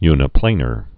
(ynĭ-plānər, -när)